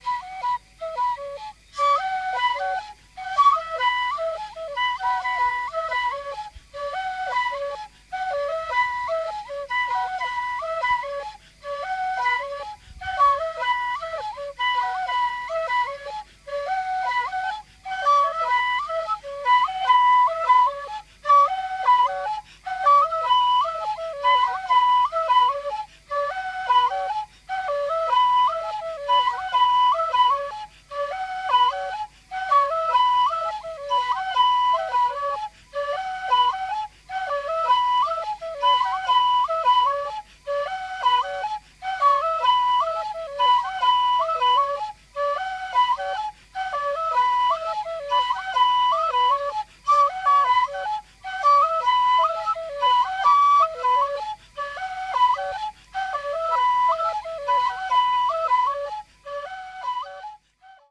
Bewegender Gesang und das packende Trommeln der Bayaka Pygmäen
Die kulturelle Essenz der Bayaka findet sich in ihren jahrhundertealten polyphonen Gesängen.
Soundscape Series by Gruenrekorder